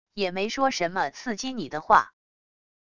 也没说什么刺激你的话wav音频生成系统WAV Audio Player